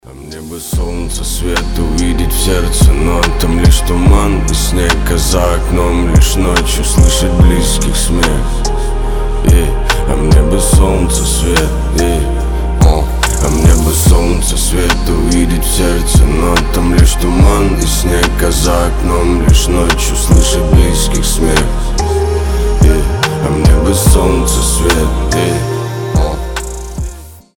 • Качество: 320, Stereo
лирика
спокойные
сильный голос